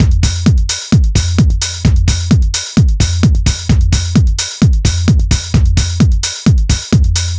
NRG 4 On The Floor 024.wav